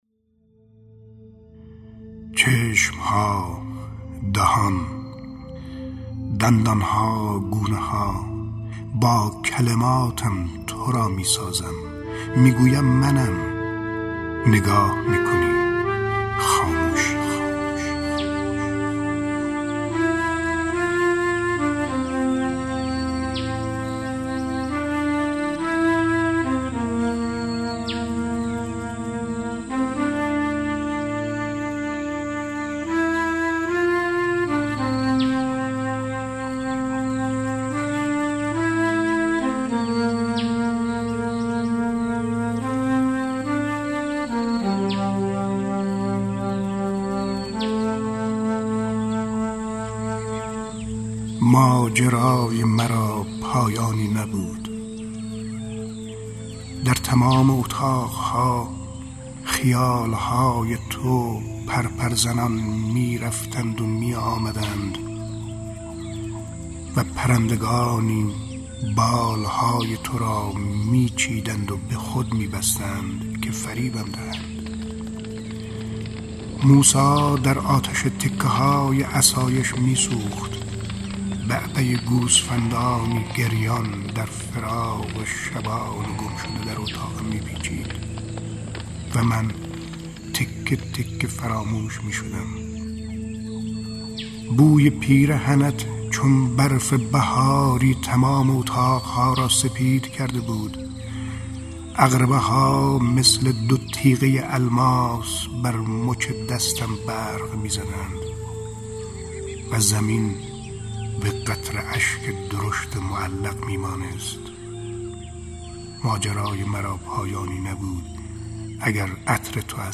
دانلود دکلمه چشم ها با صدای شمس لنگرودی با متن دکلمه
گوینده :   [شمس لنگرودی]